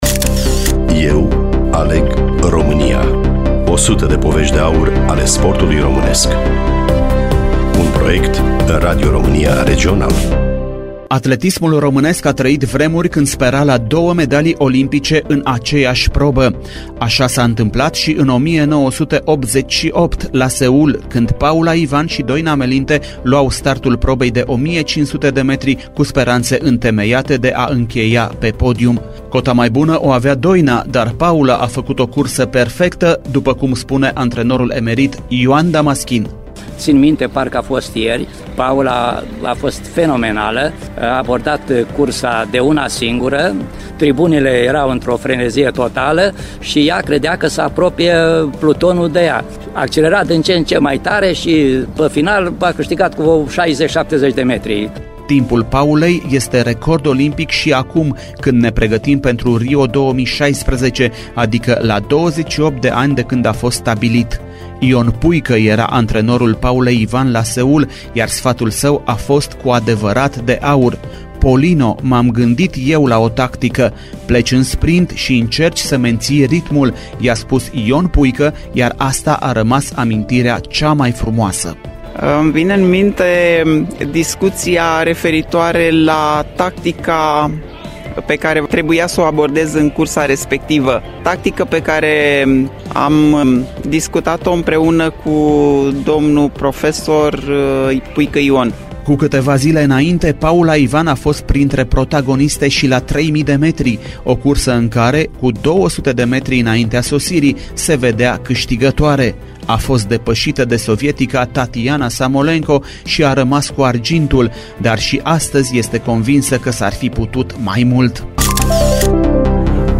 Studiul Radio Romania Timisoara
Realizator / voiceover: